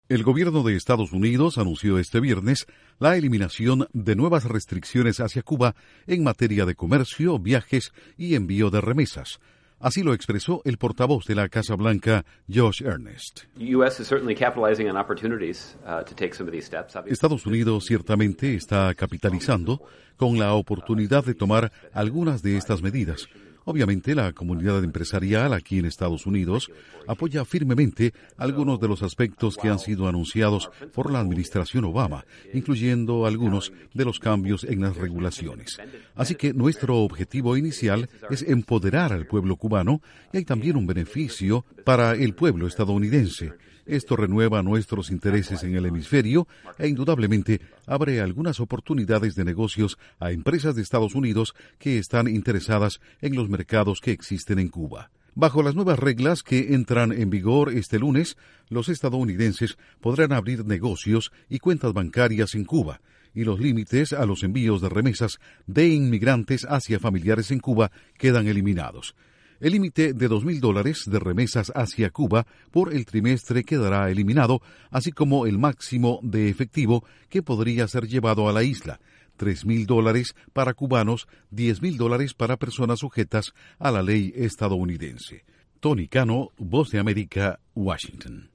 La administración Obama asegura que las nuevas medidas de apertura de Washington hacia Cuba benefician a los cubanos y a los estadounidenses. Informa desde la Voz de América en Washington